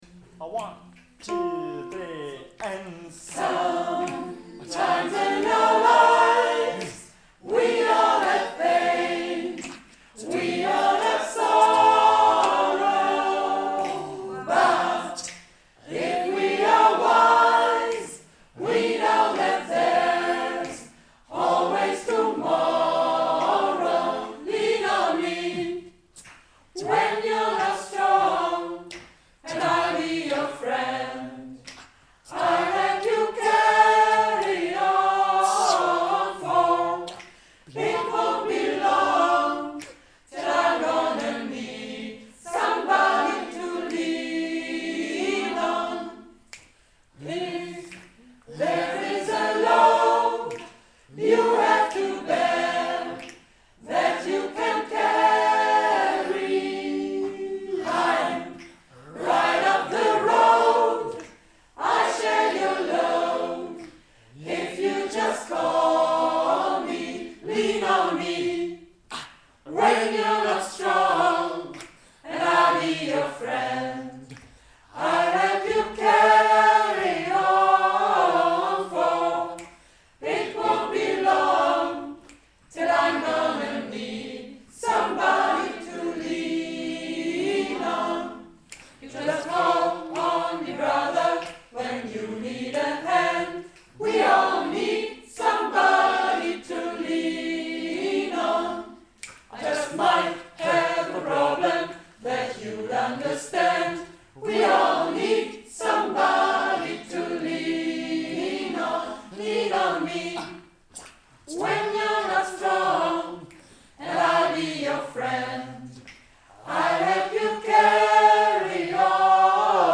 Ein paar Beispiele dafür, dass man auch mit einem kleinen Chor auf den Groove kommen kann.